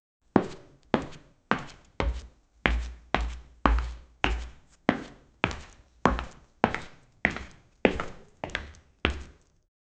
Real steel type footsteps
real-steel-type-footsteps-hnyu67jr.wav